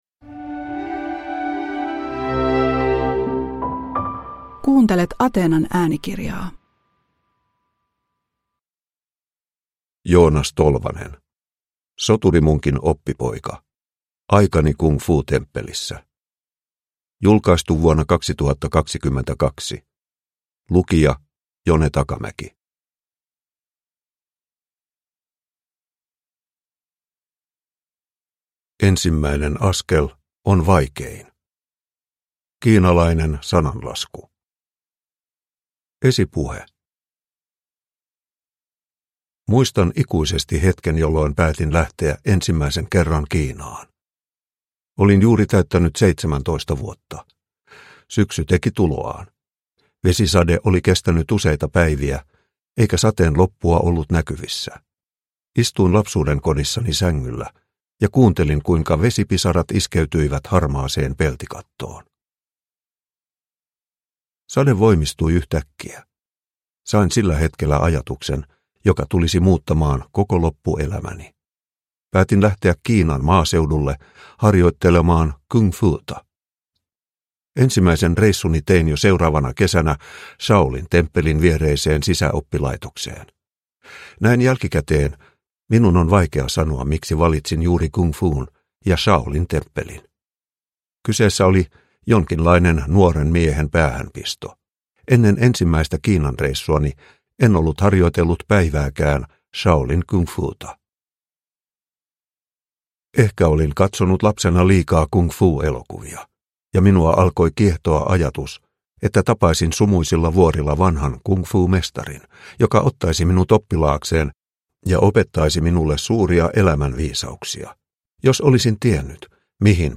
Soturimunkin oppipoika – Ljudbok – Laddas ner